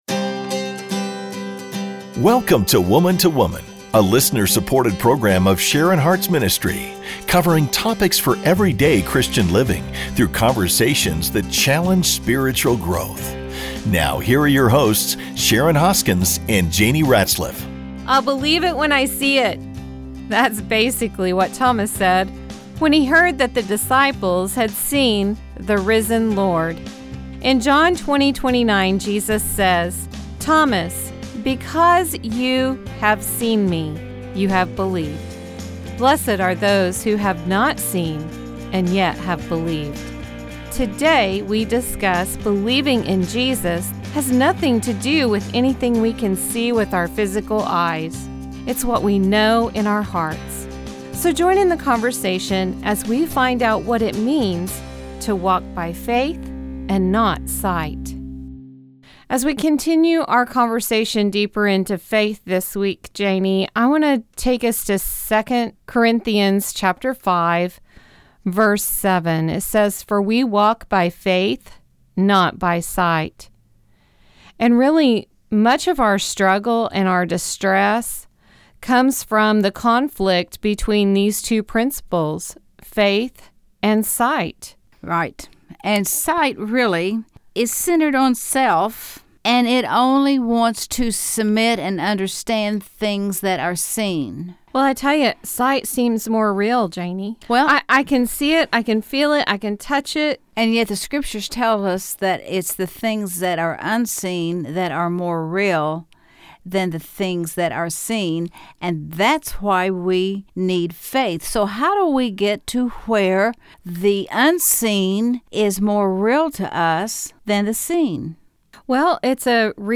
Join in the conversation as we discuss and encourage each other to walk by faith, not sight.